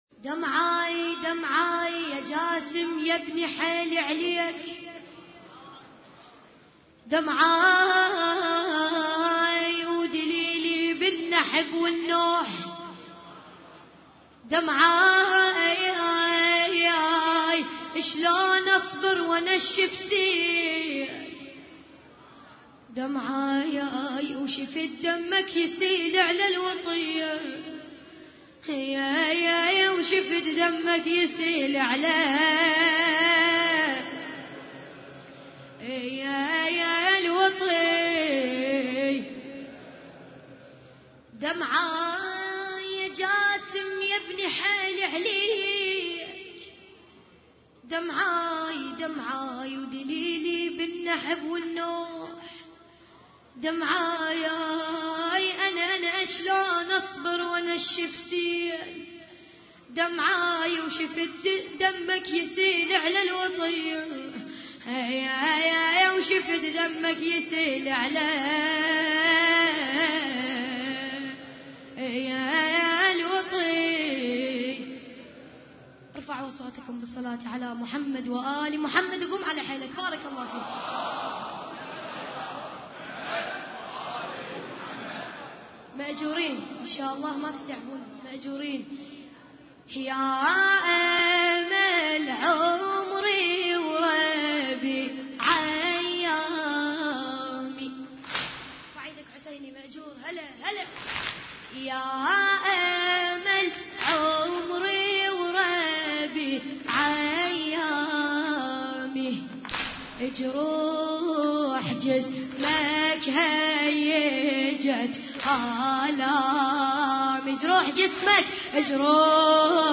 عزاء ليلة 8 محرم 1433 هجري